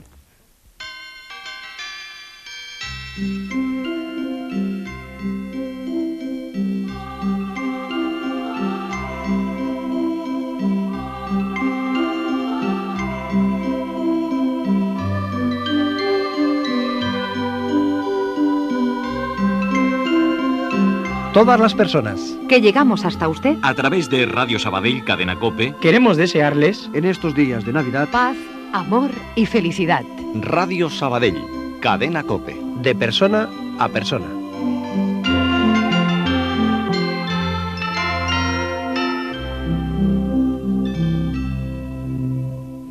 Felicitació de Nadal amb les veus de Ràdio Sabadell